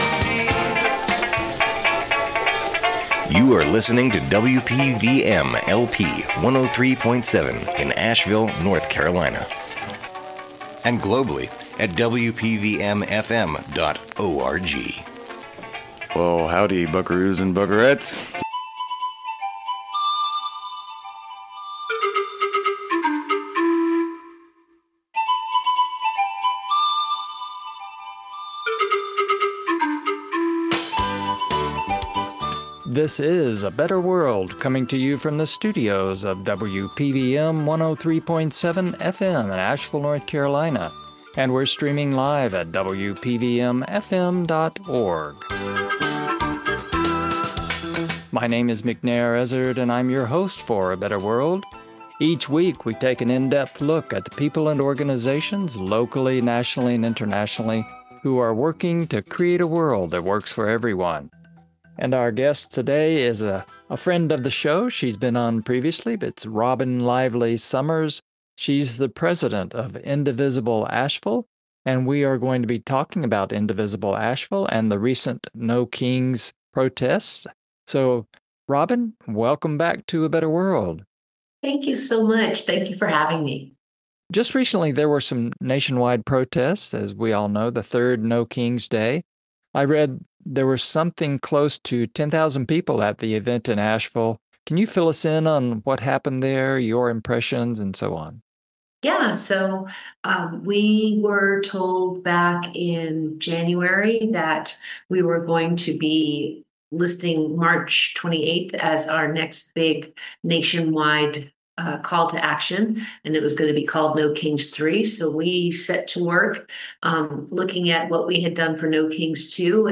No Kings 3 Protest & Indivisible Asheville interview on A Better World Podcast